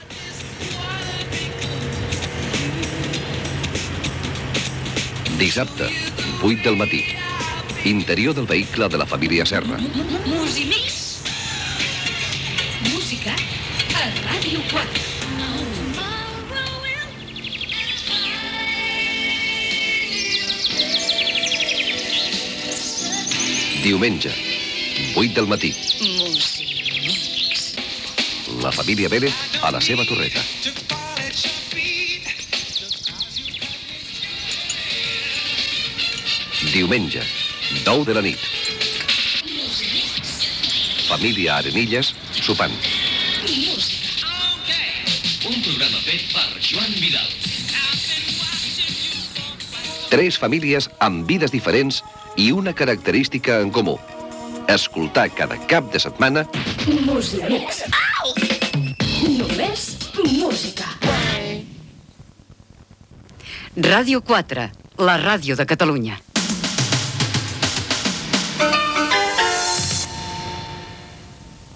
Promoció del programa i indicatiu de Ràdio 4